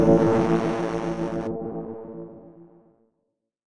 dronescuff.ogg